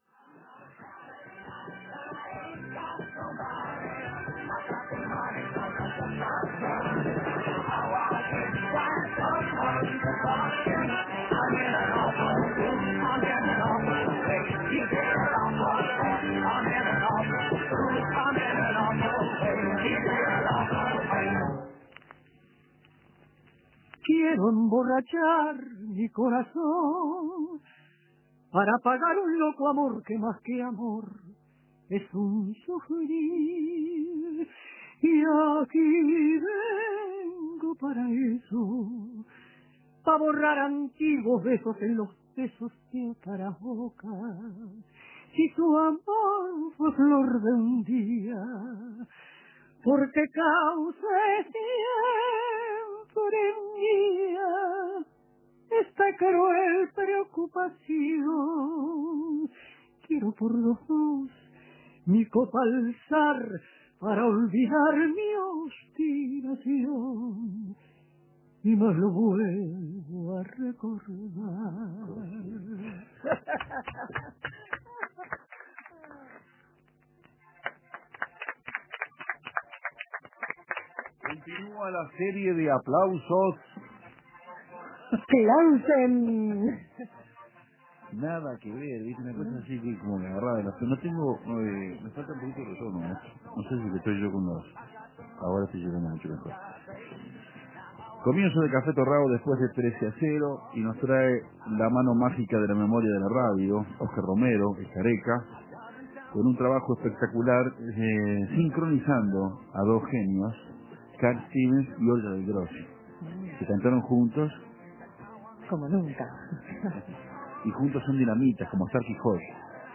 Unos días antes, el sábado 21, Café Torrado también le dio rienda suelta a la nostalgia. Hubo música y testimonios...